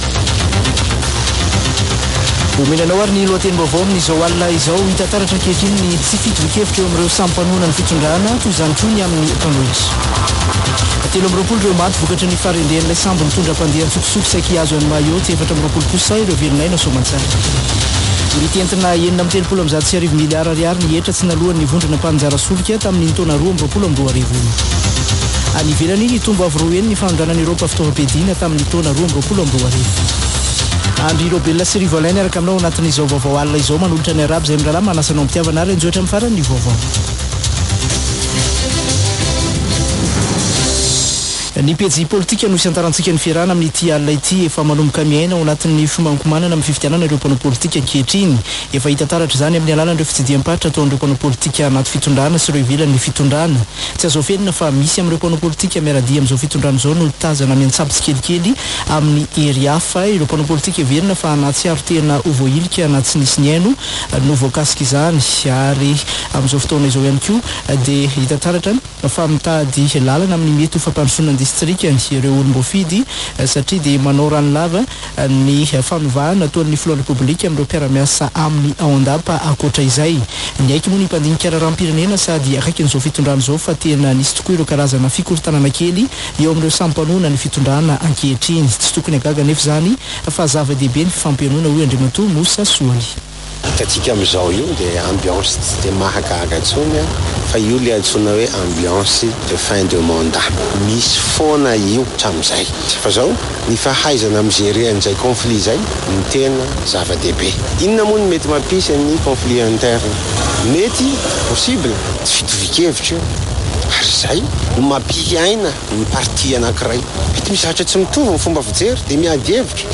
[Vaovao hariva] Alatsinainy 13 marsa 2023